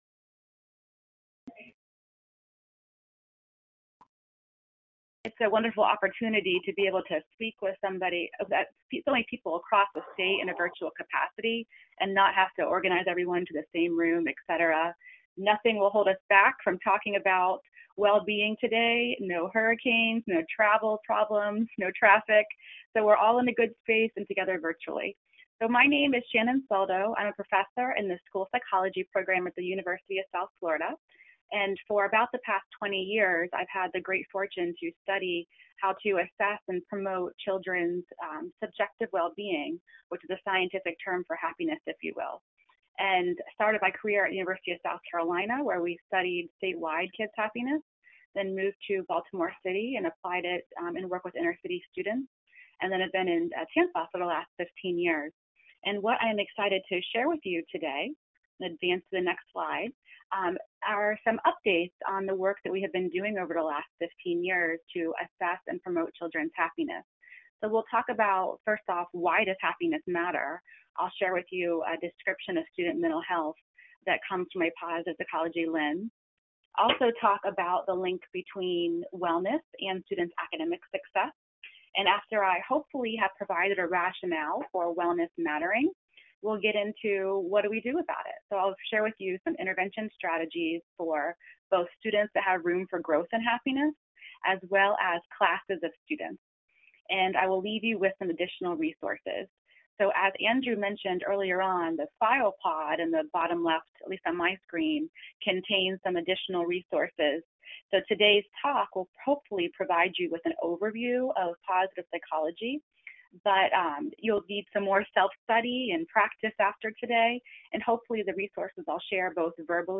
Webinar: Using Positive Psychology Assessment & Interventions